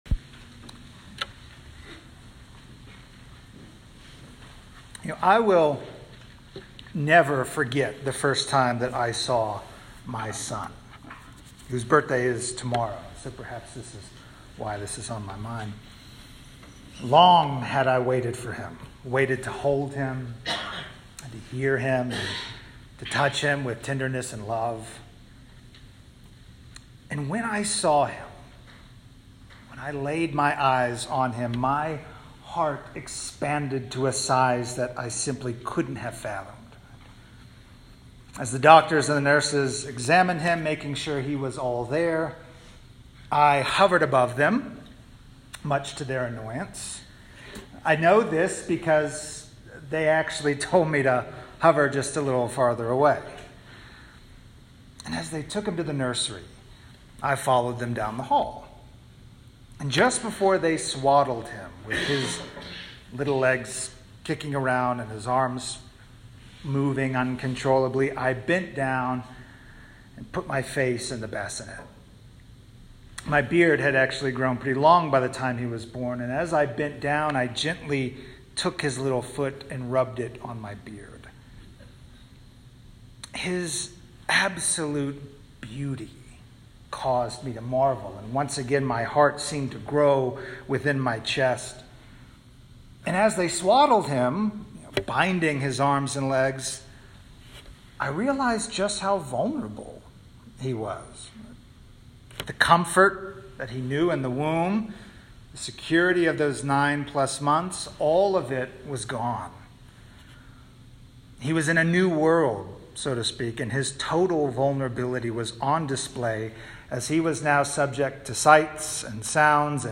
Sermons 2018-2020
Christmas Day: December 25, 2019